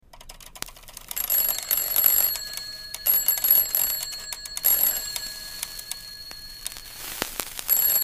A close-up of a classic alarm clock vigorously ringing. Suddenly, tiny, colorful fireworks begin to erupt from the top of the clock.
The ASMR blends the loud, jarring ring of the alarm clock with the faint crackling and fizzing sounds of the tiny fireworks.